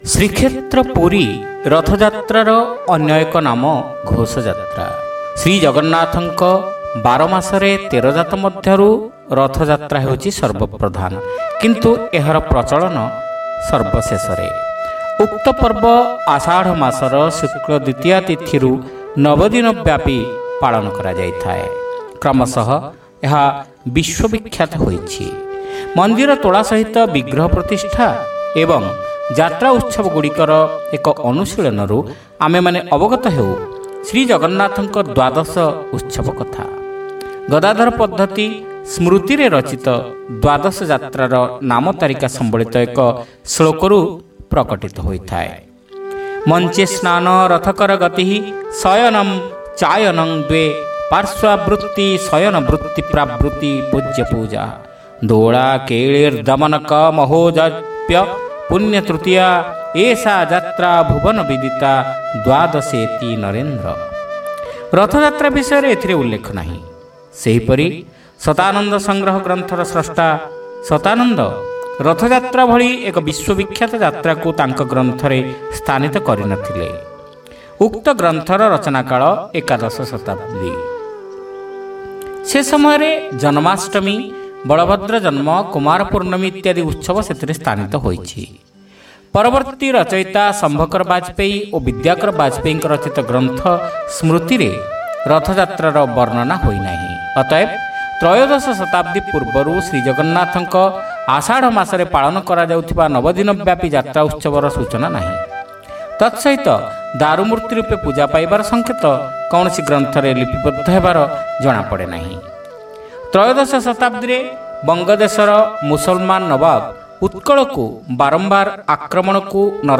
ଶ୍ରାବ୍ୟ ଗଳ୍ପ : ରଥଯାତ୍ରାର ବୈଶିଷ୍ଟ୍ୟ